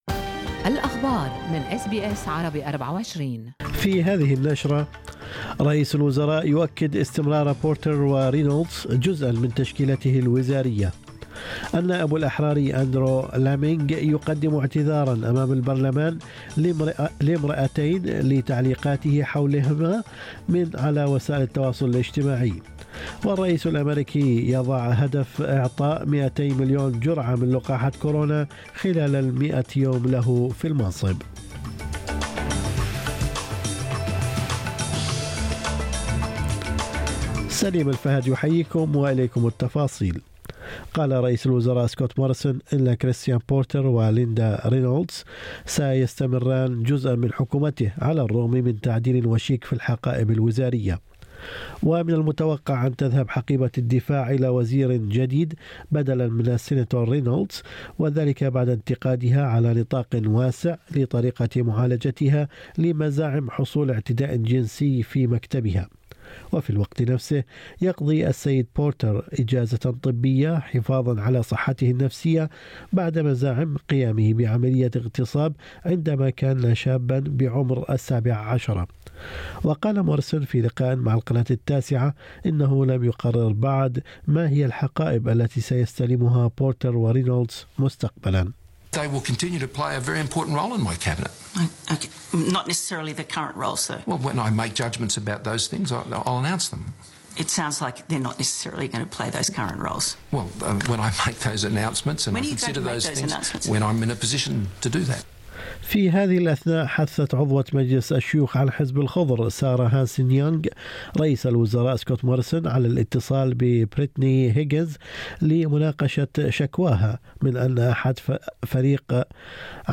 نشرة أخبار الصباح 26/3/2021